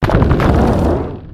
Cri de Géolithe dans Pokémon X et Y.